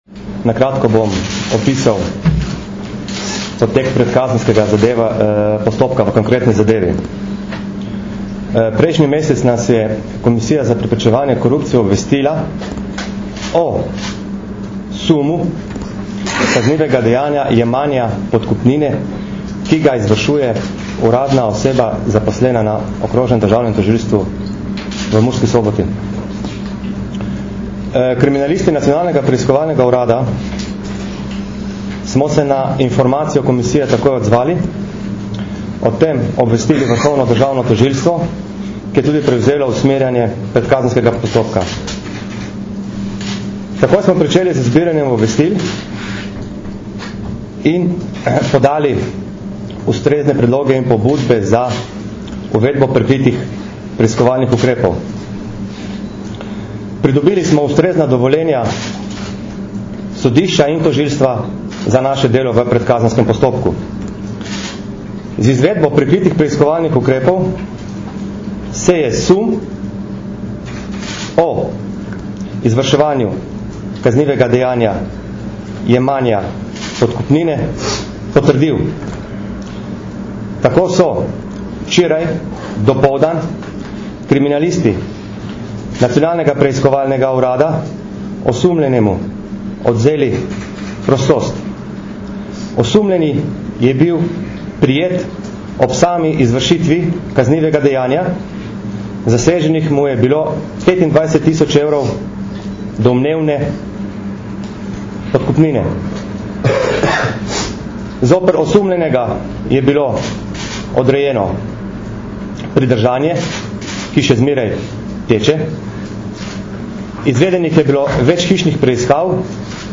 Zvočni posnetek izjave mag. Harija Furlana, direktorja Nacionalnega preiskovalnega urada (mp3)